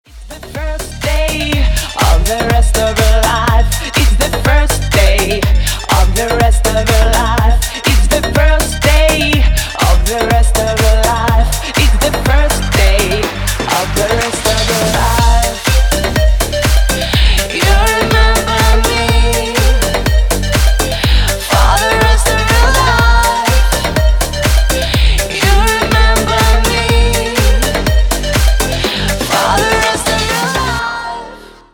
• Качество: 320, Stereo
женский вокал
dance
Club House